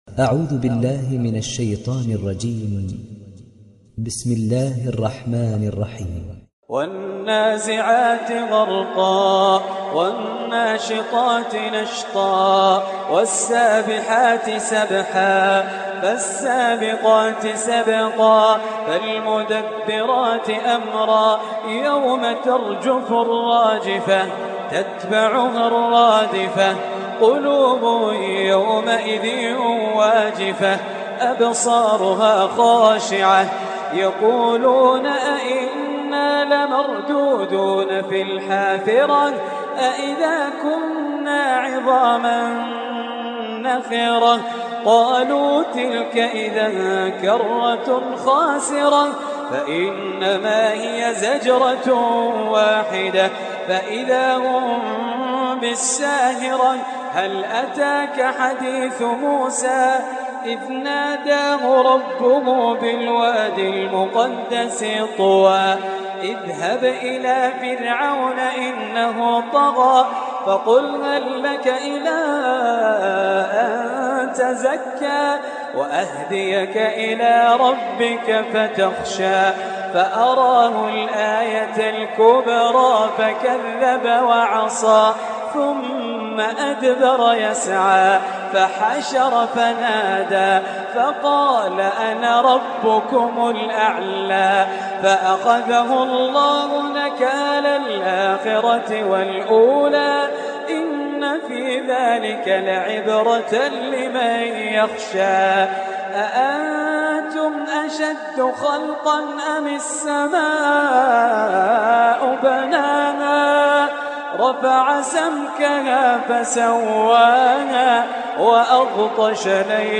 تحميل سورة النازعات mp3 بصوت خالد الجليل برواية حفص عن عاصم, تحميل استماع القرآن الكريم على الجوال mp3 كاملا بروابط مباشرة وسريعة